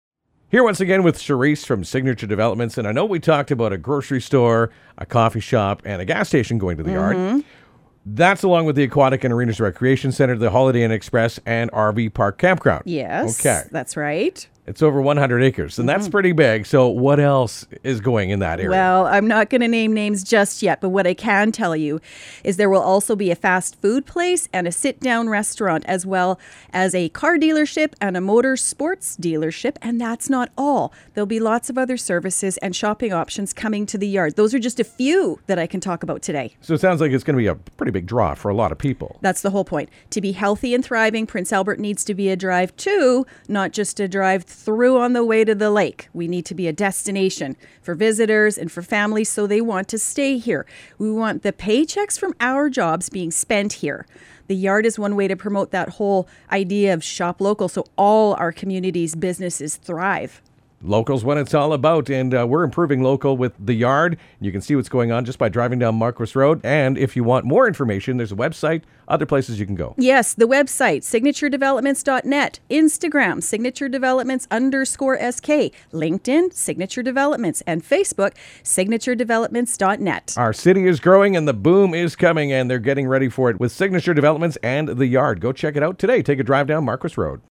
Radio Chat